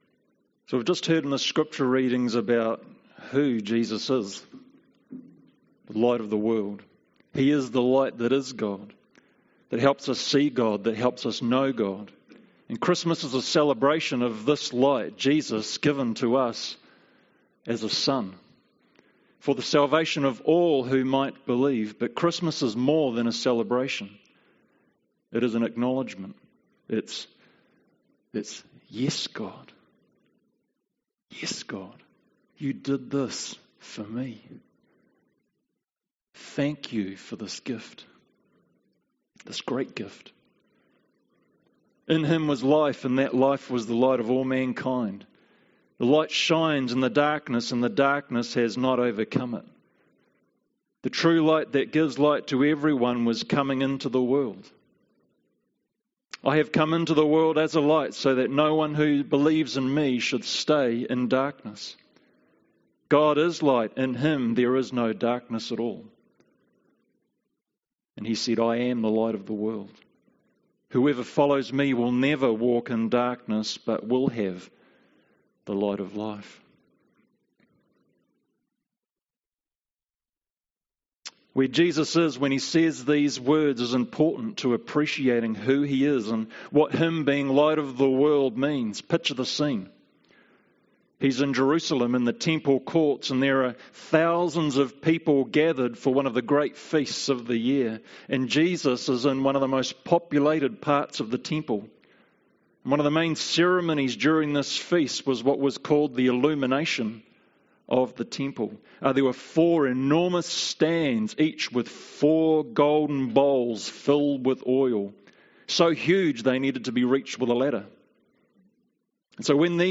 Christmas Day Service
Sermon 2019-12-25